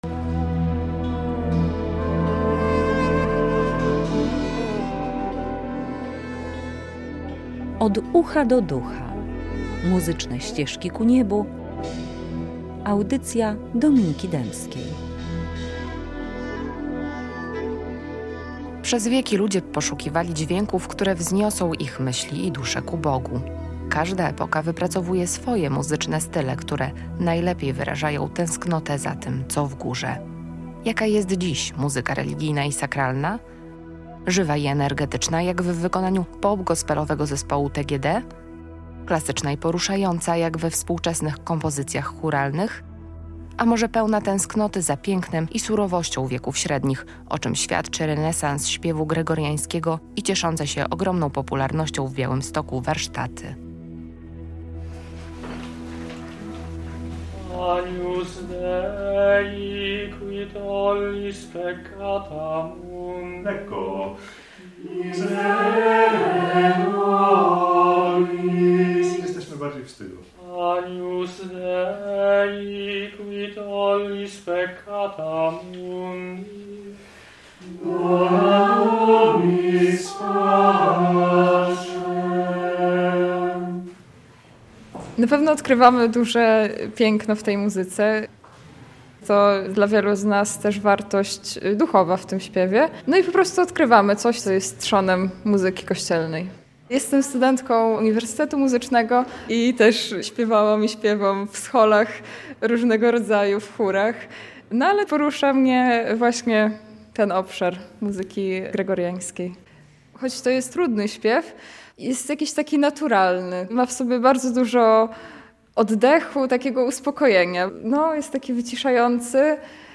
Radio Białystok | Reportaż | "Od ucha do ducha.